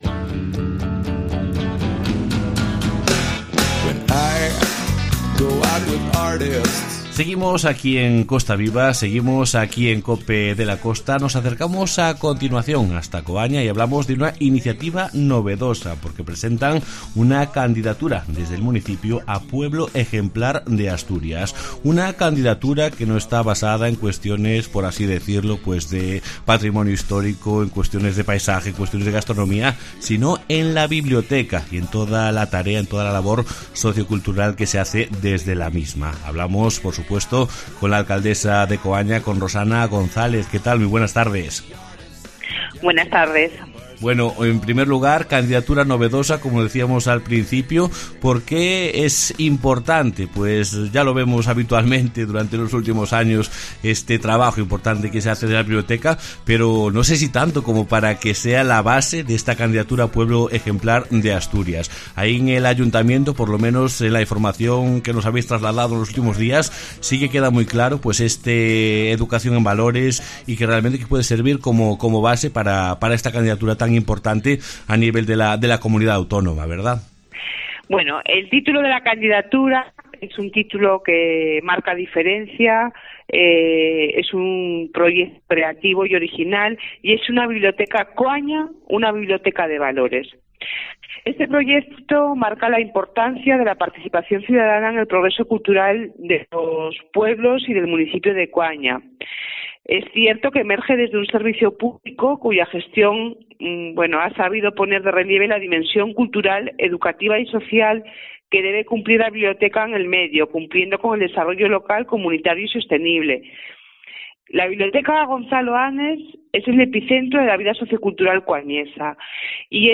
AUDIO: La alcaldesa de Coaña habló en COPE de la Costa de la novedosa candidatura de su concejo a Pueblo Ejemplar de Asturias, basada en la labor...